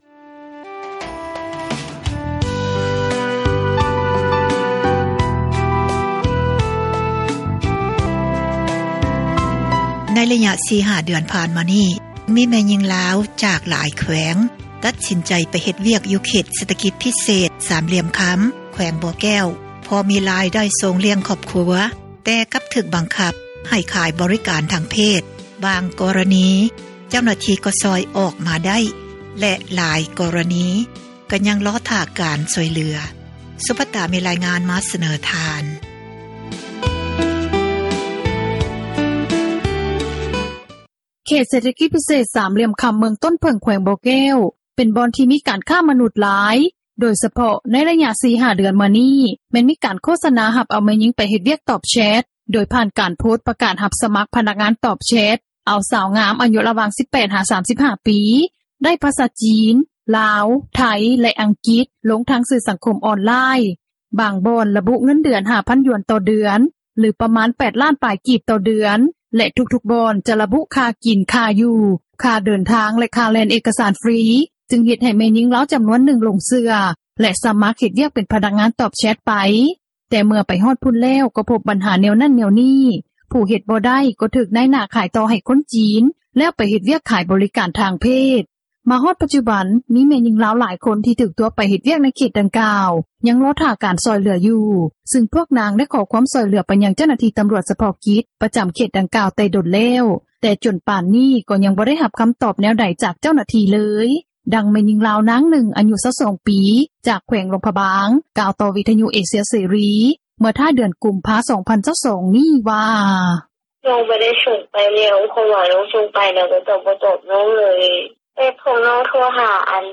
ນາງຜູ້ນີ້ ທັງເວົ້າທັງໄຫ້ ອີກຕື່ມວ່າ ເມື່ອຕິດຕໍ່ຫາເຈົ້າໜ້າທີ່ບໍ່ໄດ້ ນາງກໍເລີຍກໍເລີຍຕິດຕໍ່ຫາທາງຄອບຄົວ ເພື່ອໃຫ້ຄອບຄົວຫາທາງຊ່ອຍເຫຼືອ ໃຫ້ນາງ ແລະ ນ້ອງສາວໄດ້ກັບບ້ານ.